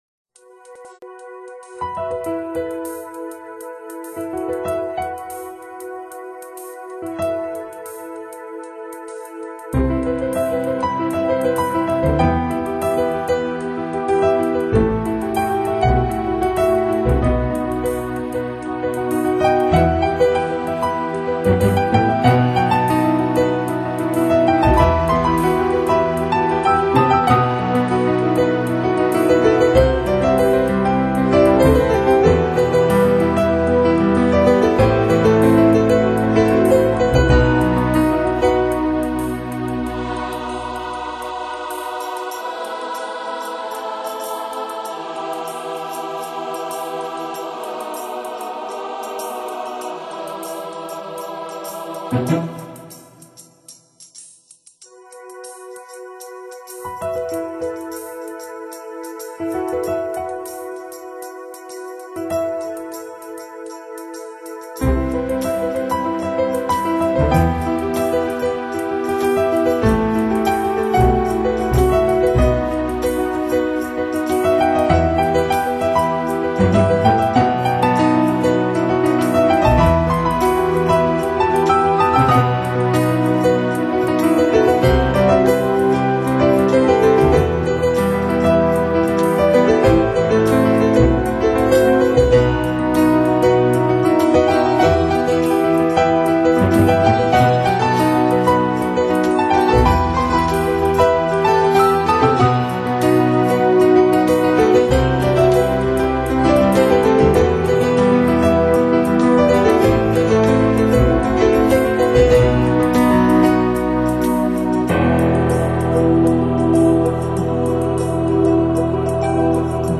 主题鲜明的钢琴演奏专辑，琢磨出钢琴静谧、激情的双面美感